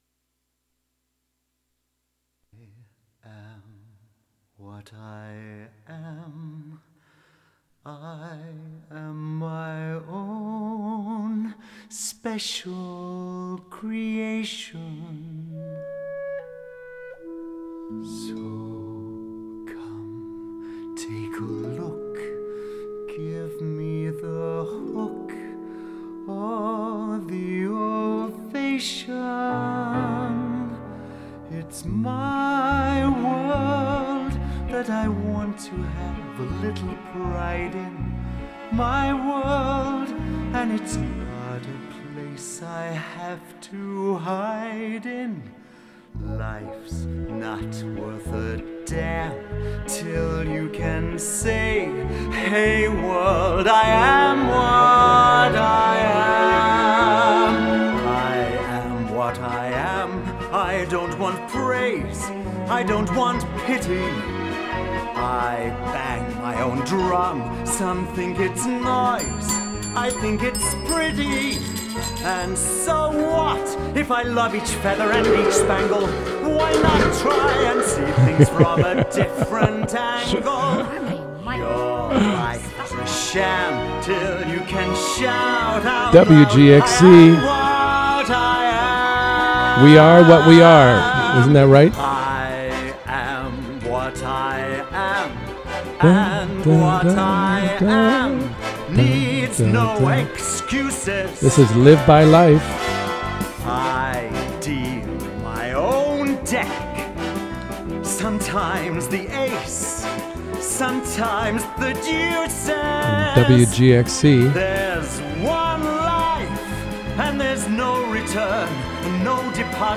Produced by the Camphill Hudson Radio Group.
Members of the Camphill Hudson Radio Group speak with special guest